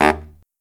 LOHITSAX15-L.wav